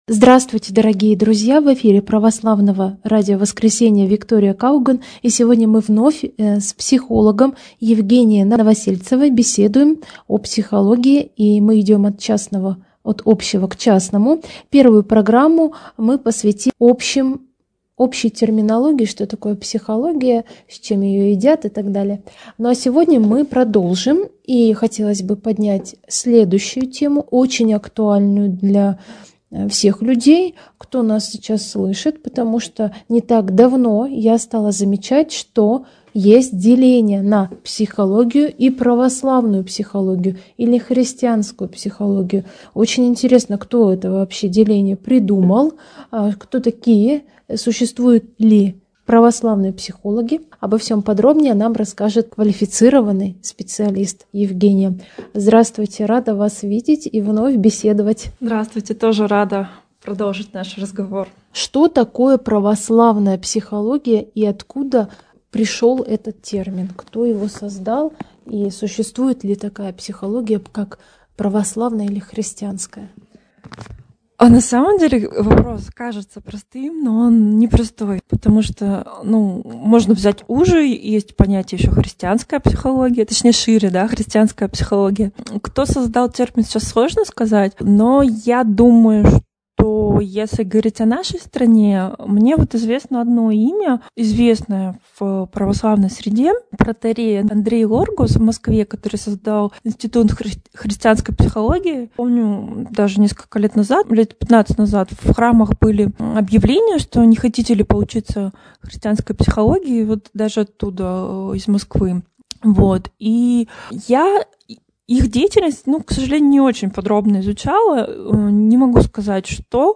Второе интервью с психологом